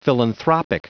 Prononciation du mot philanthropic en anglais (fichier audio)
Prononciation du mot : philanthropic
philanthropic.wav